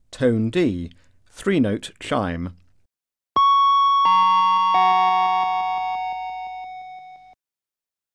Alert Tone: D